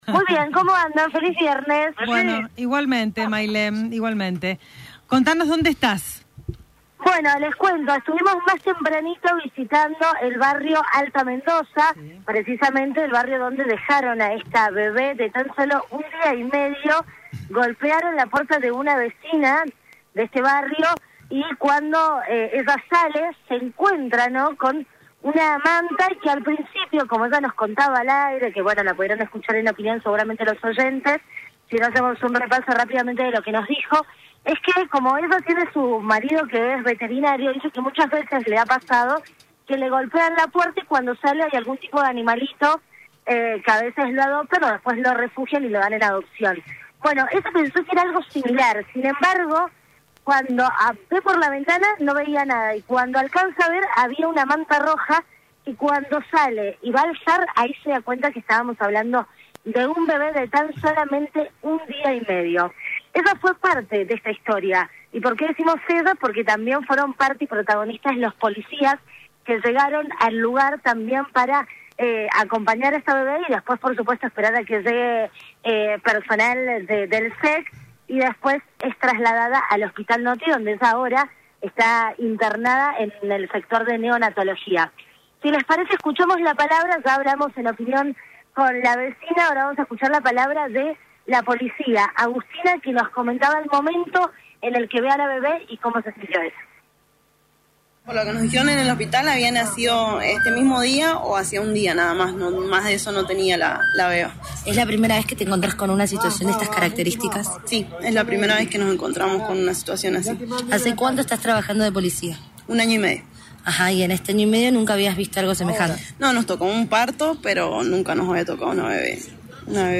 Móvil de LVDiez desde Barrio Alto Mendoza